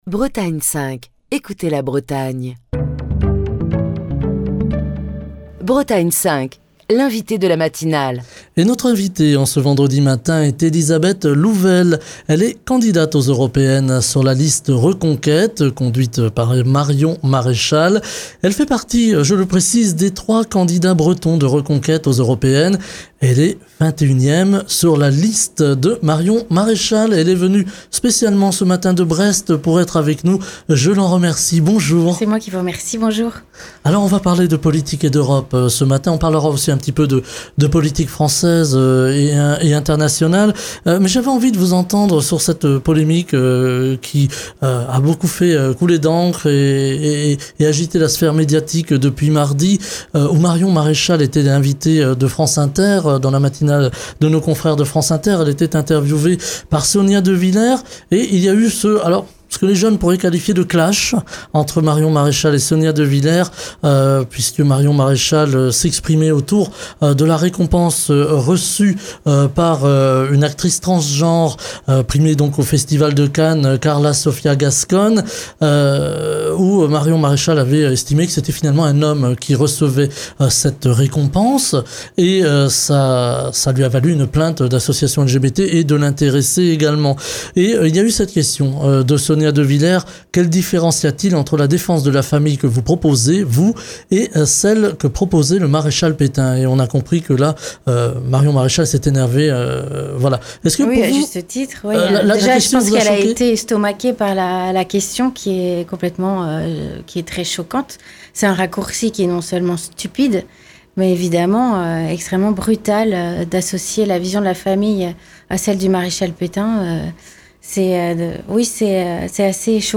est l'invitée de la matinale de Bretagne 5.